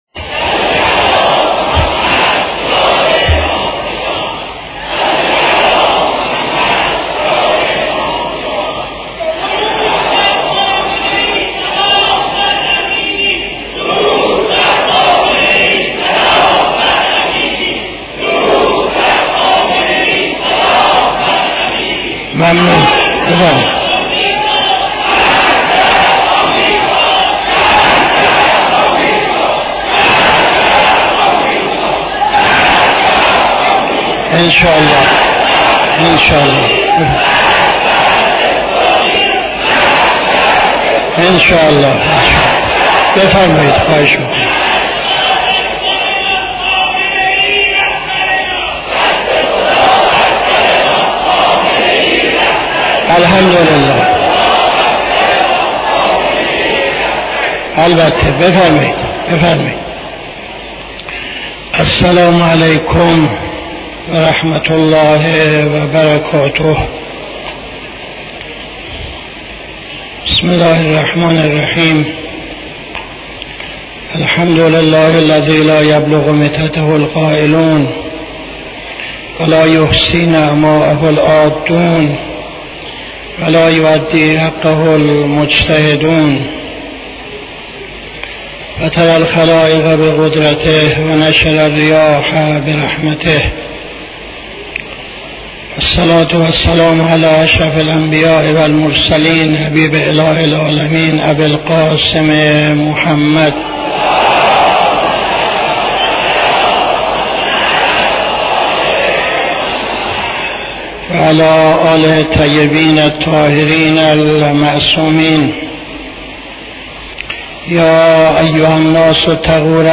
خطبه اول نماز جمعه 10-07-77